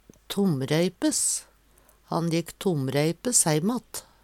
tomreipes - Numedalsmål (en-US)